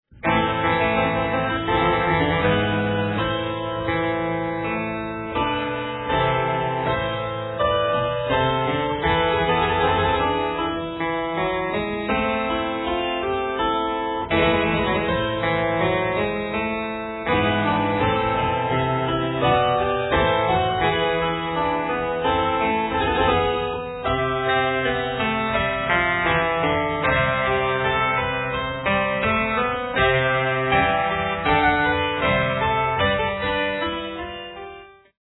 Early stereo master tapes, recorded at 30 ips